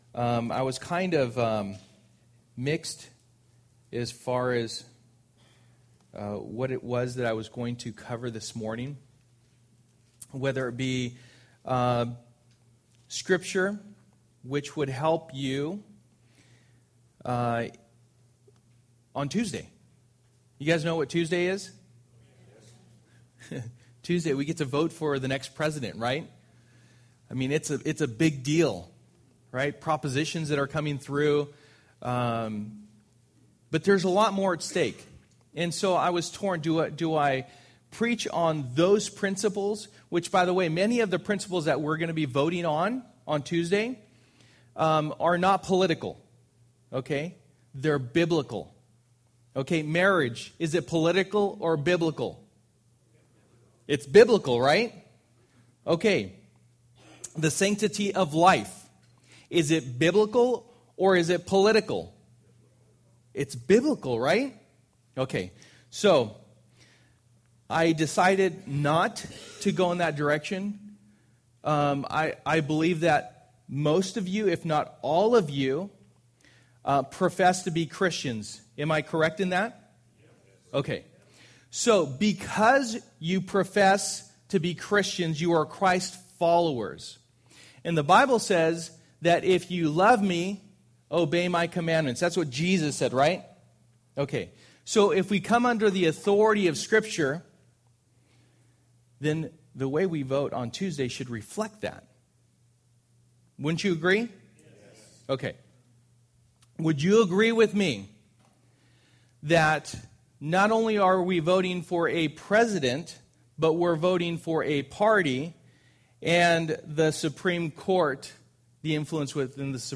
A United Church Passage: Ephesians 5:22-33 Service: Sunday Morning %todo_render% « Who’s Hand Are You Holding?